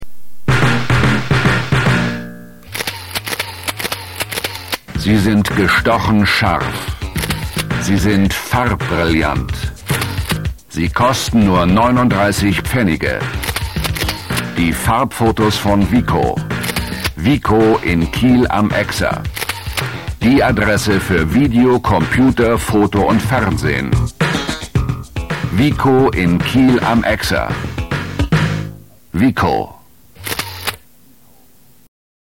deutscher Schauspieler und Sprecher.
norddeutsch
Sprechprobe: Industrie (Muttersprache):